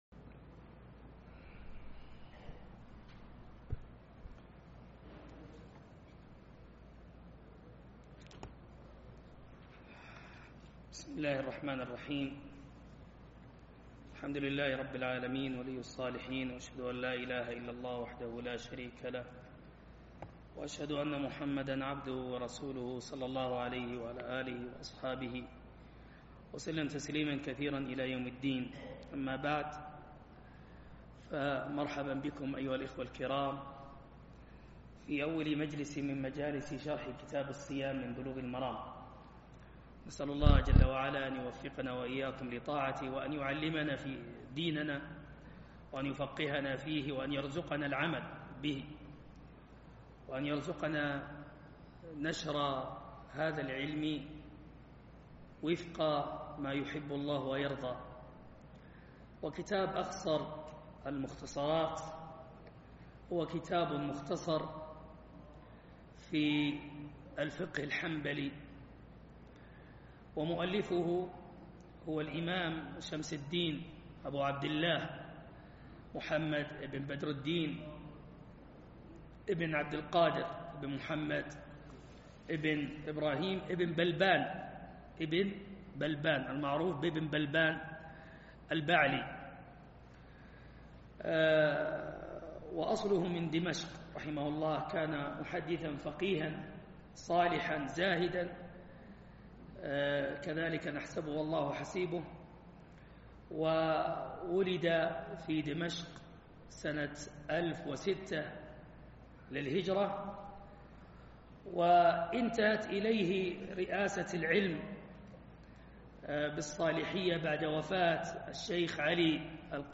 درس شرح الصيام من أخصر المختصرات